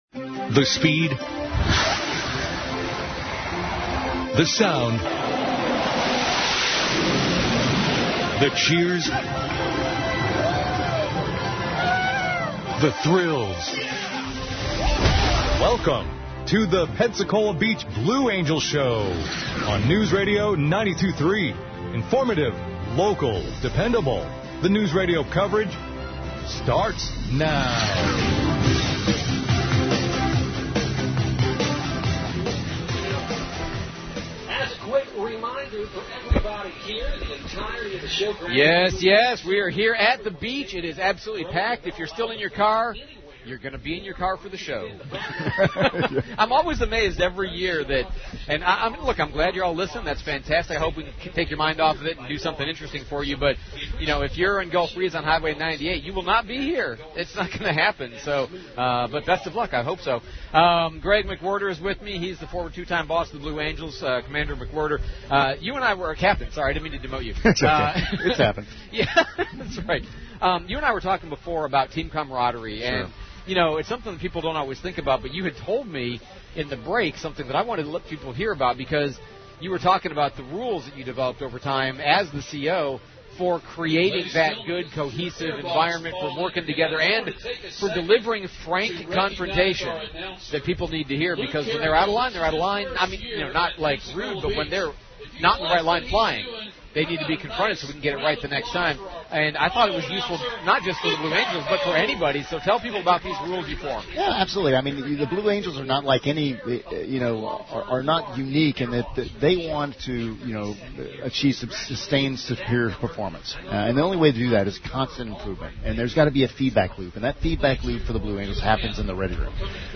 Pensacola Beach Airshow